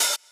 hat01.ogg